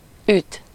次閉次前圓唇元音[1]是個介于閉前圓唇元音/y/和半開前圓唇元音/ø/之間的圓唇元音
匈牙利语[29] öt
[ʏ˕t̪] 一般记作⟨y⟩。参见匈牙利语音系